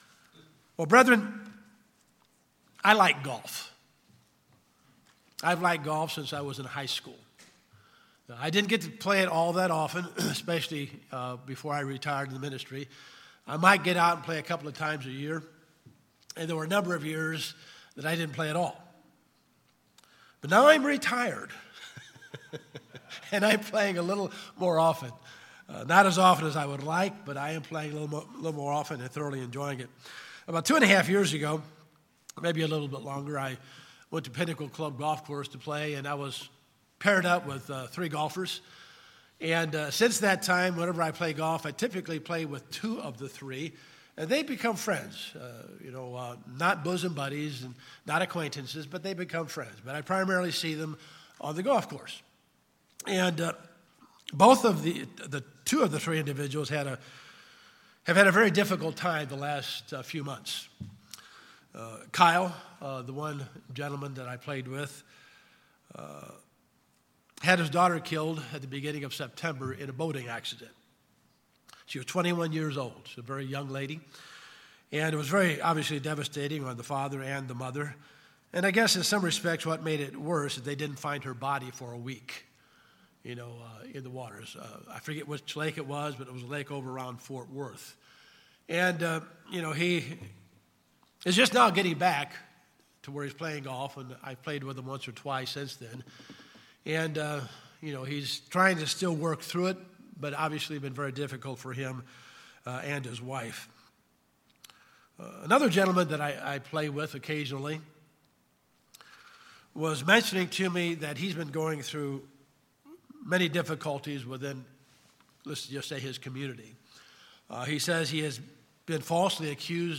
This sermon focuses on practical steps and biblical principles to remember when facing false accusation. Mistreatment of this sort reveals our true character. With a focus on God and a proper response, our trials become an opportunity for Christian growth.